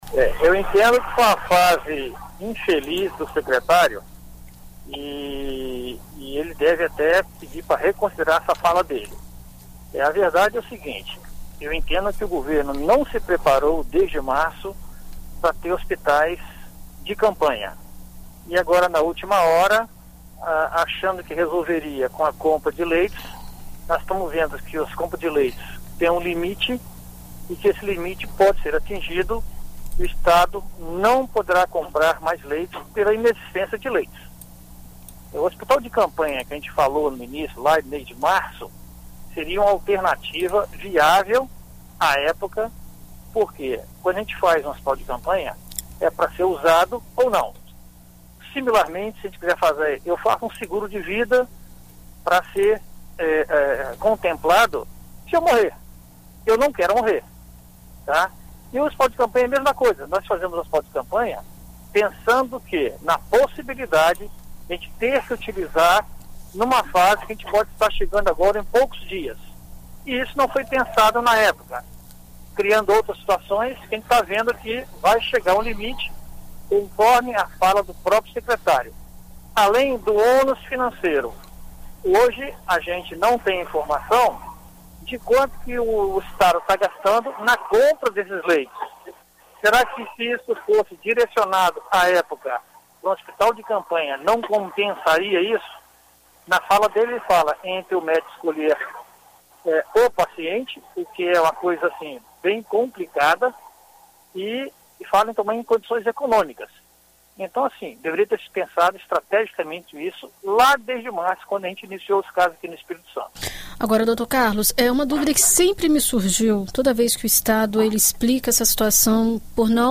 Na BandNews FM, clínico geral analisa situação de pacientes que dependem de UTI, além do contágio feito por assintomáticos